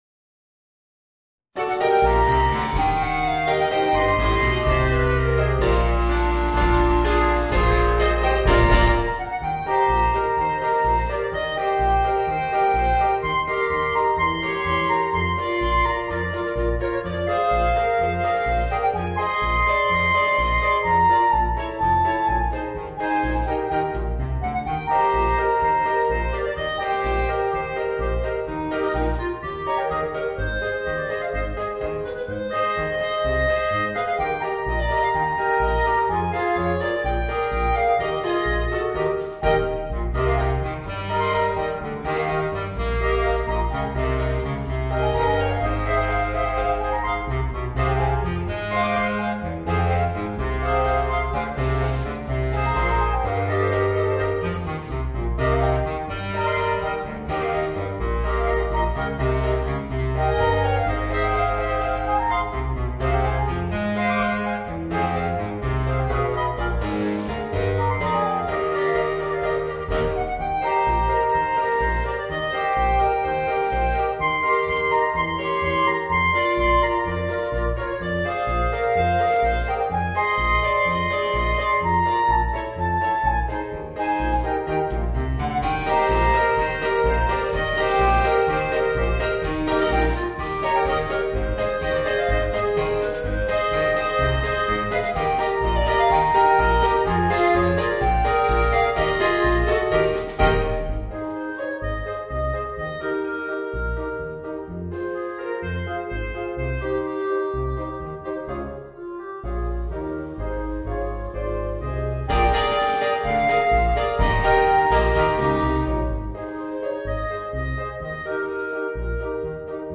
Voicing: 4 Clarinets and Piano